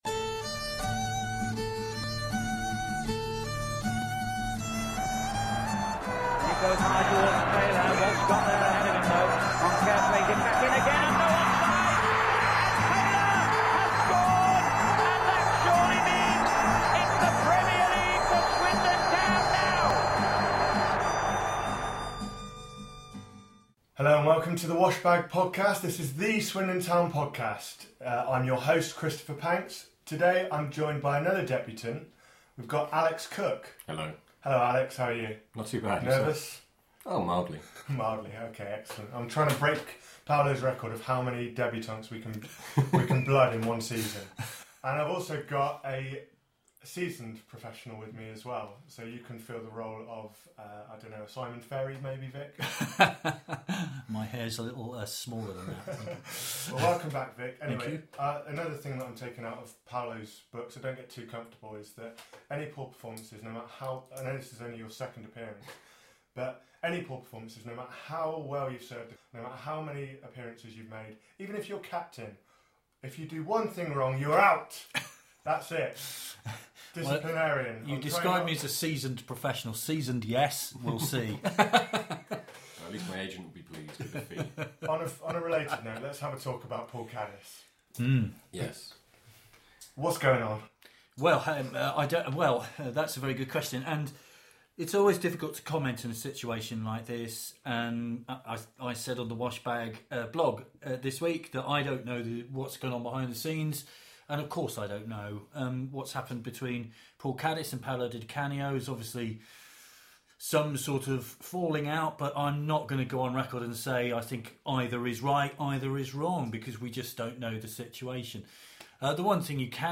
Podcast Episode 4: Unbeaten start without Caddis, plus Nicky Summerbee interview